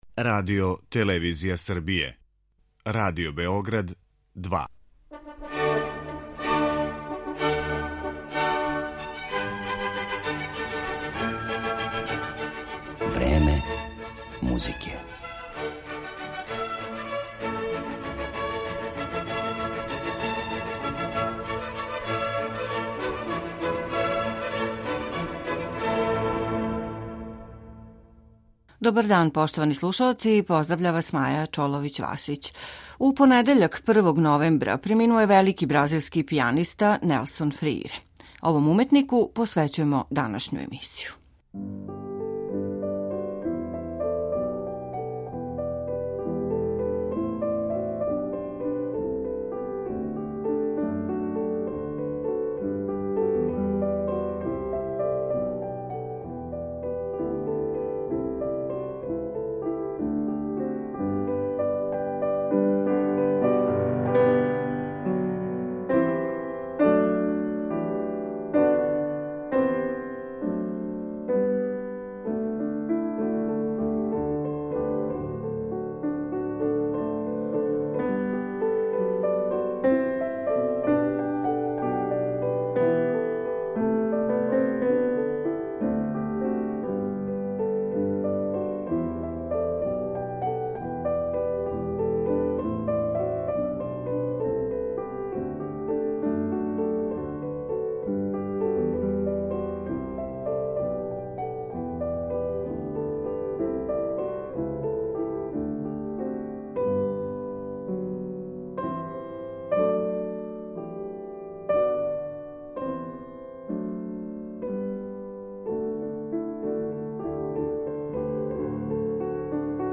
Тим поводом, овом пијанисти посвећујемо данашњу емисију.